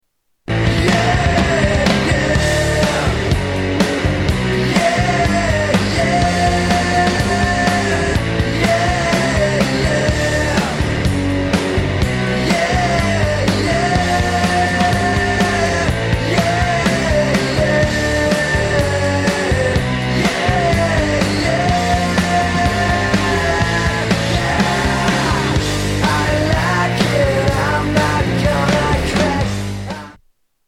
Tags: Sound Effects Rock Truetone Ringtones Music Rock Songs